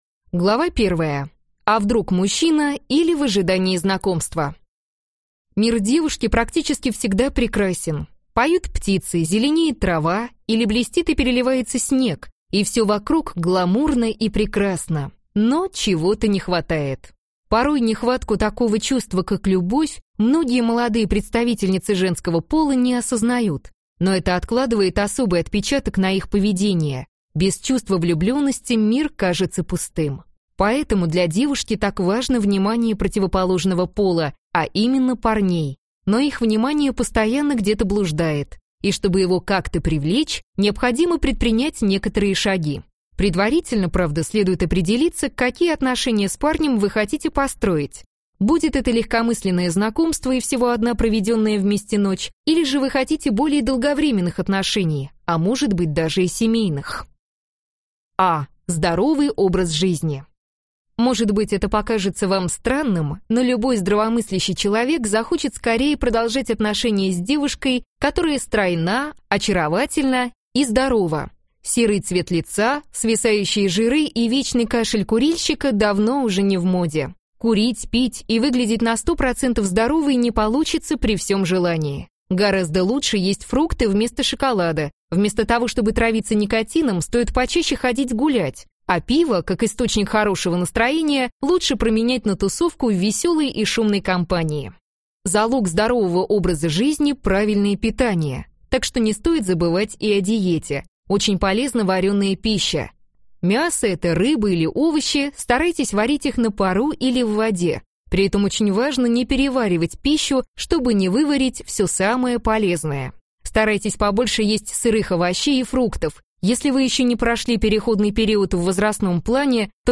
Аудиокнига Как познакомиться с парнем | Библиотека аудиокниг